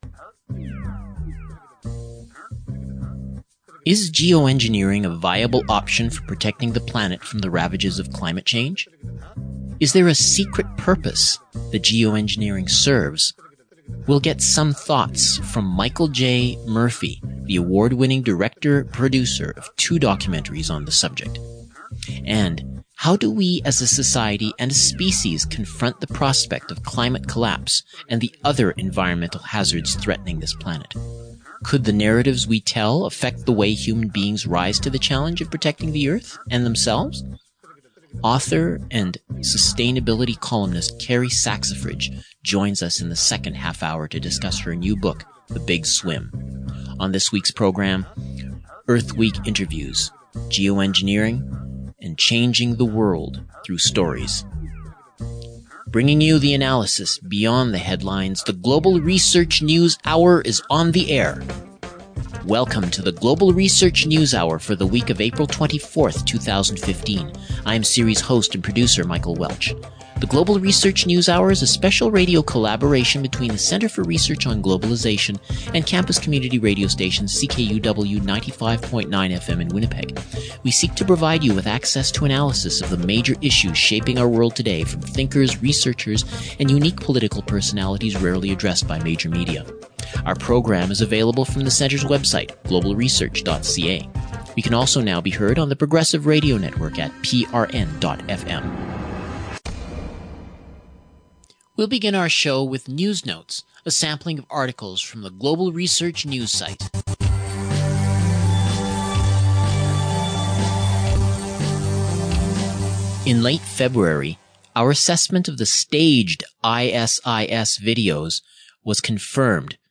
Earth Week Interviews: Geo-Engineering & Changing the World through Stories